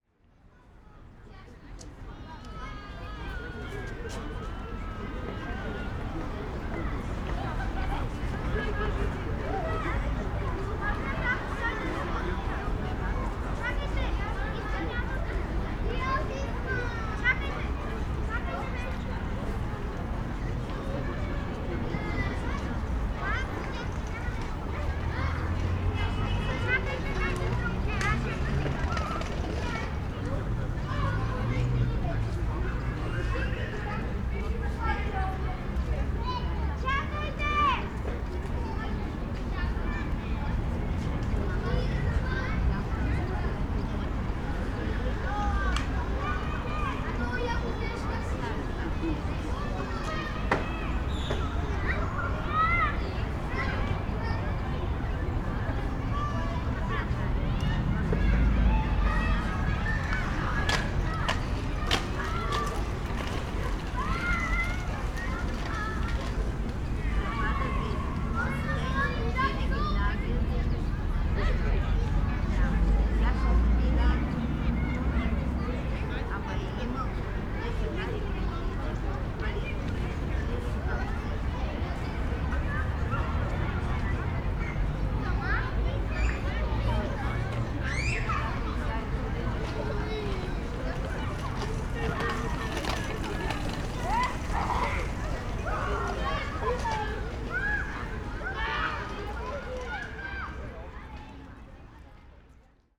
ambience
City Park Ambience - Crowd 3 Kids